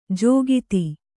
♪ jōgiti